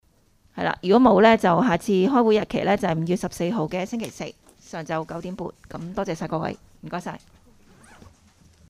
委员会会议的录音记录
地点: 九龙长沙湾道303号 长沙湾政府合署4字楼 深水埗区议会会议室